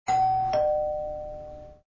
amzn_sfx_doorbell_chime_02.mp3